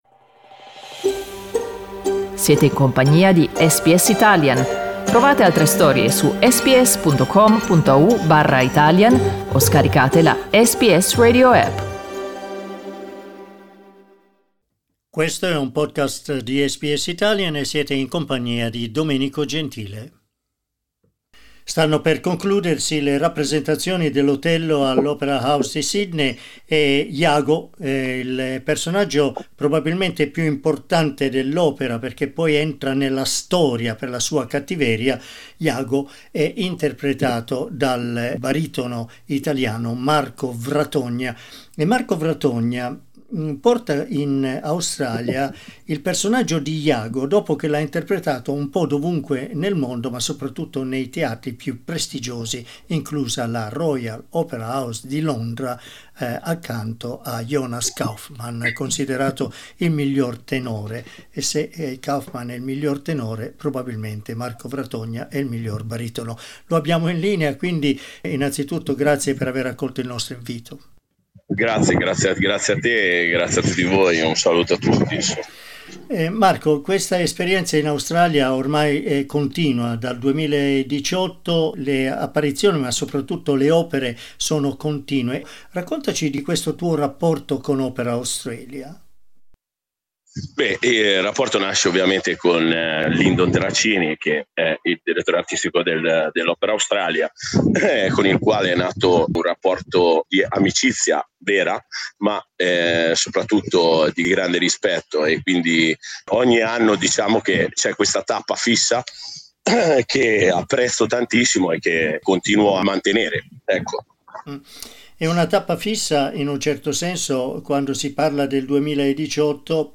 In questa conversazione Marco Vratogna ci parla del personaggio shakespeariano e di come lo interpreta.